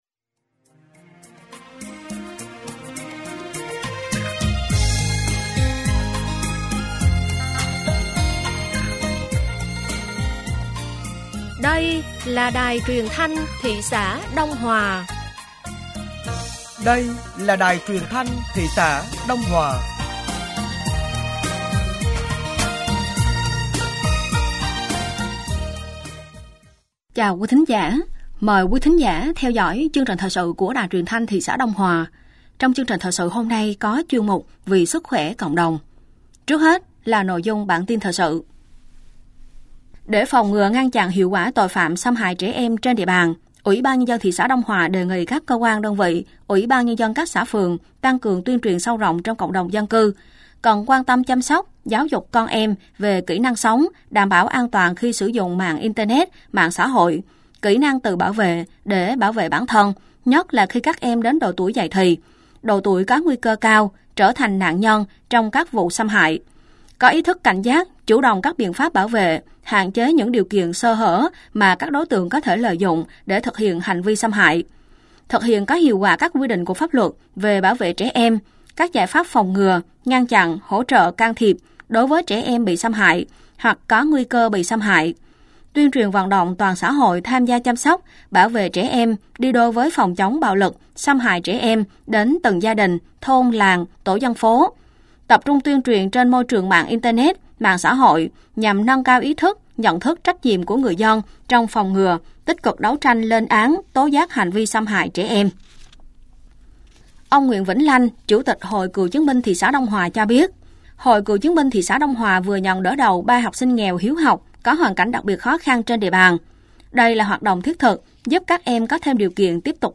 Thời sự tối ngày 10 và sáng ngày 11 tháng 9 nămn 2024